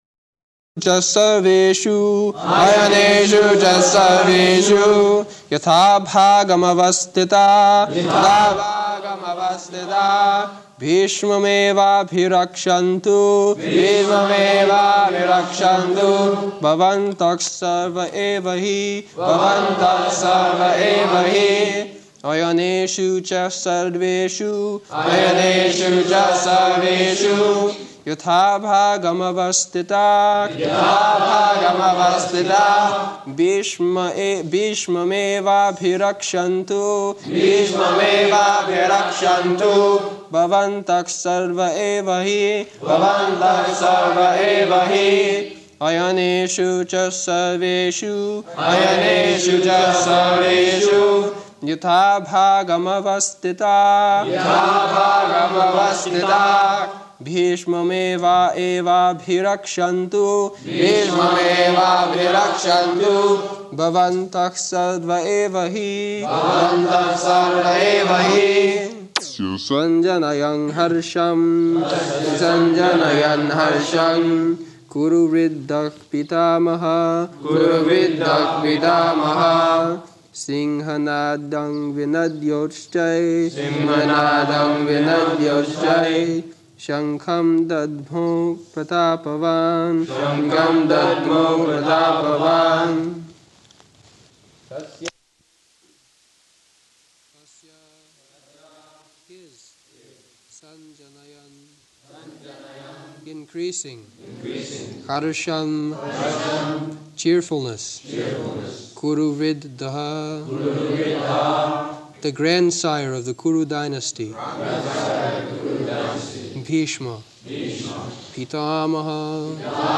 Location: London
[leads chanting of verse] [Prabhupāda and devotees repeat]